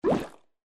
shield_remove.mp3